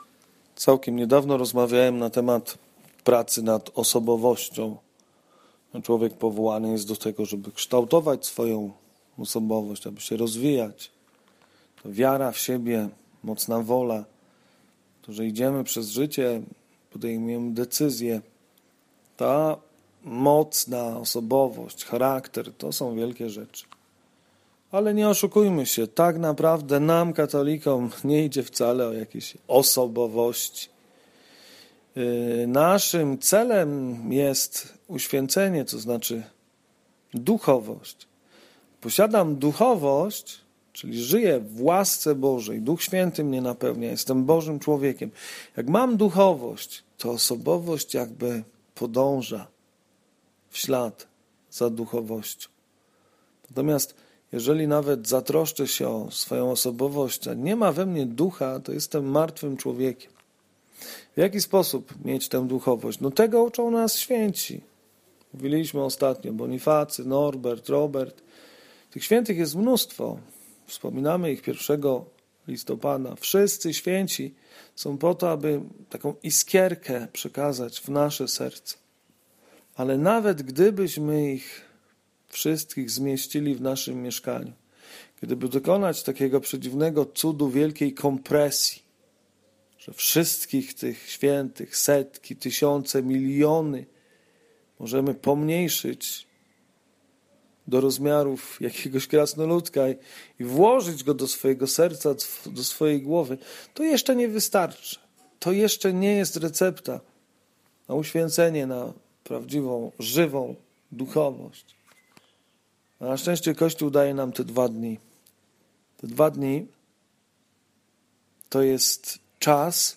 Komentarz do Ewangelii z dnia 8 czerwca 2018